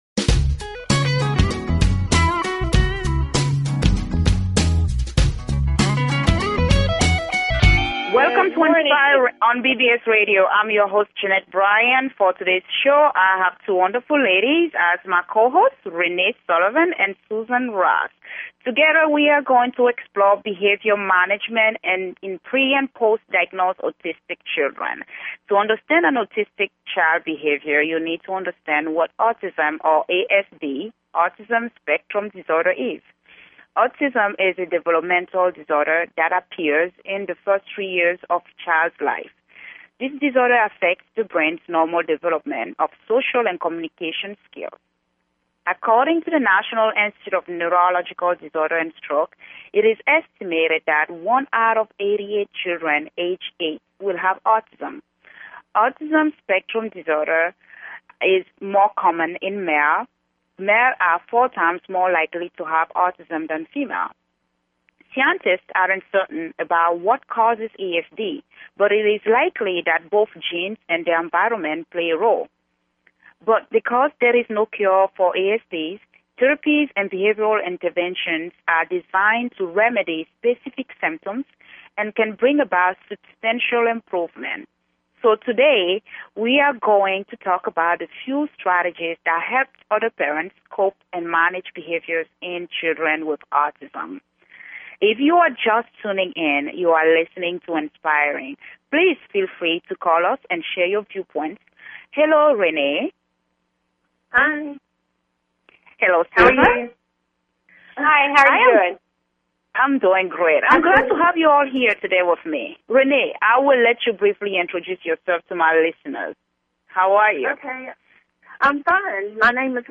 Talk Show Episode, Audio Podcast, Inspiring and Courtesy of BBS Radio on , show guests , about , categorized as